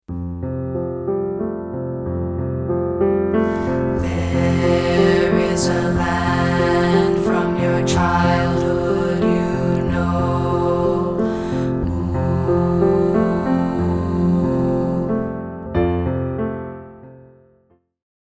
Here's a rehearsal track of part 3A, isolated.